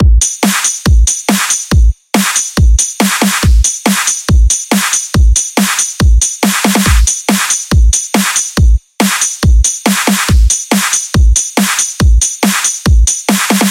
描述：一个经典的Kick amp; snare鼓循环，用于Dubstep ETC)
Tag: 140 bpm Dubstep Loops Drum Loops 2.31 MB wav Key : C Ableton Live